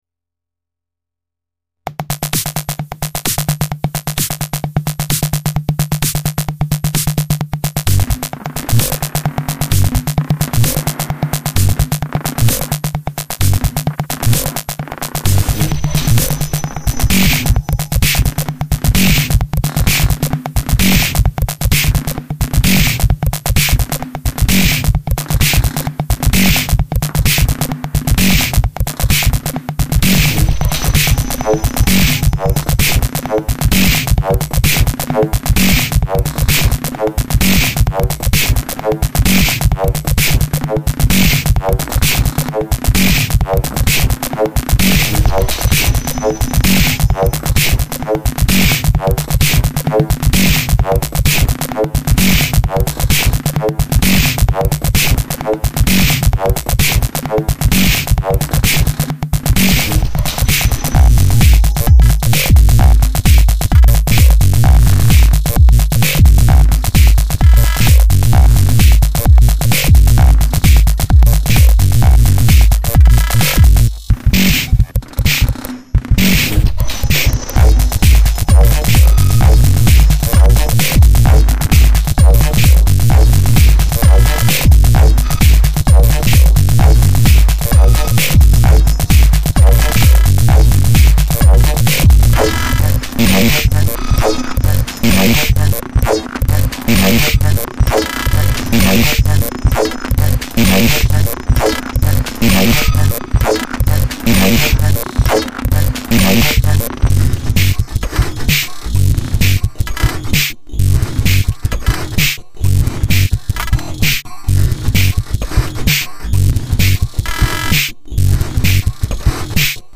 ansonsten rockig groovig usw. !
auch wieder mal interessantes sounddesign ( ich hab da immer wieder die assoziation von rhytmisch zerberstenden metalsplittern ) .
mag ich weil viel Drumming and groove.
technofunkpunk.
Das schrickelt wirklich, klingt ziemlich detroitig abgefahren und geht gut voran.